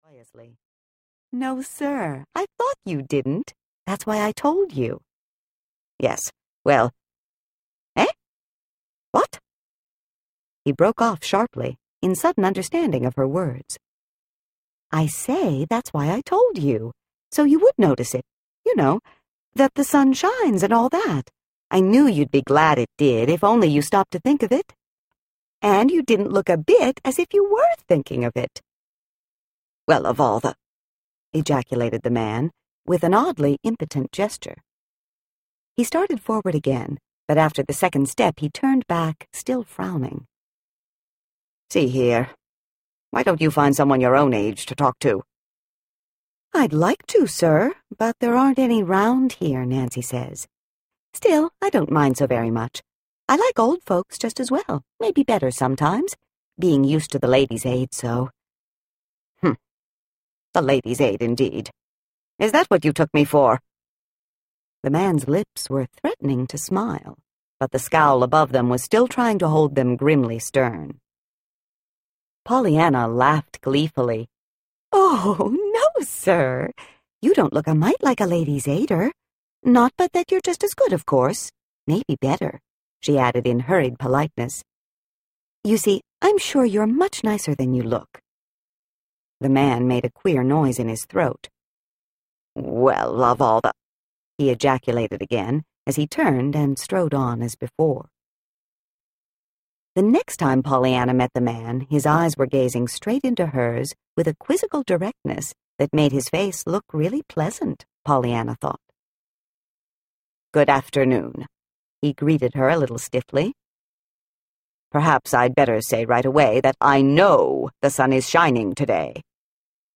Pollyanna Audiobook
Narrator
7.0 Hrs. – Unabridged